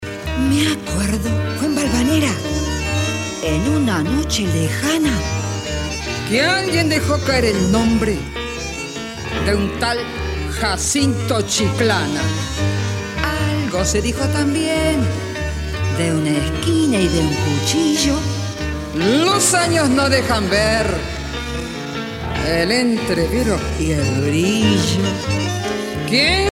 danse : tango (Argentine, Uruguay)
Pièce musicale éditée